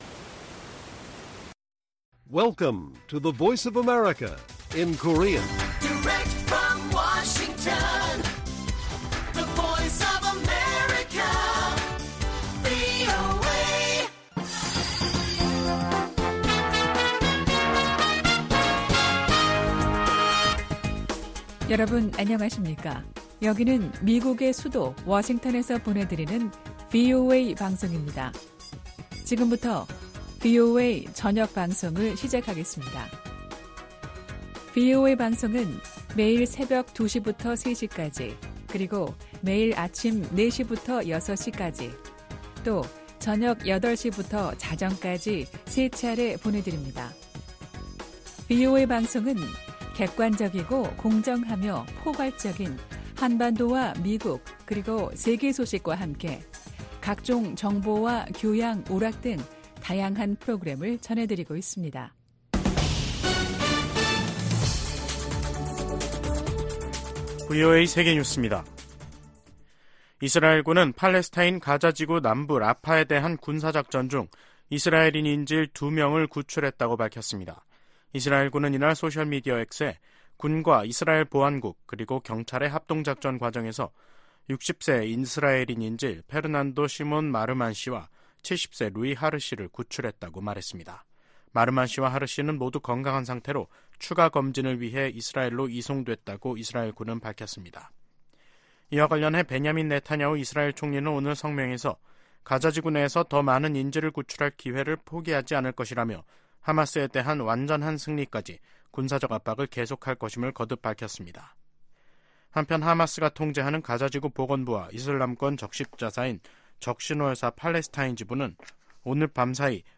VOA 한국어 간판 뉴스 프로그램 '뉴스 투데이', 2024년 2월 12일 1부 방송입니다. 북한 국방과학원이 조종 방사포탄과 탄도 조종체계를 새로 개발하는 데 성공했다고 조선중앙통신이 보도했습니다. 지난해 조 바이든 행정부는 총 11차례, 출범 이후 연간 가장 많은 독자 대북제재를 단행한 것으로 나타났습니다. 미국과 한국·일본의 북한 미사일 경보 정보 공유는 전례 없는 3국 안보 협력의 상징이라고 미 국방부가 강조했습니다.